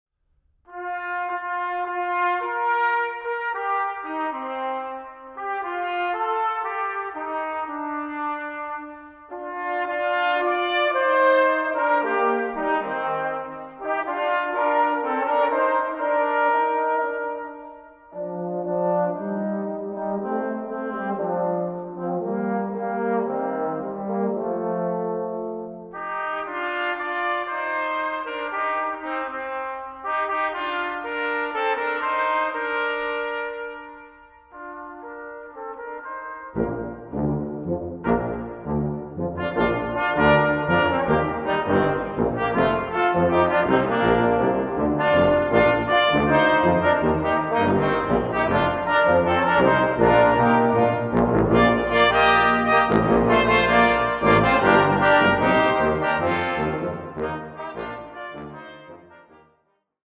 Brass Version